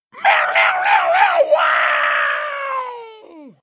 mouth-guitar_08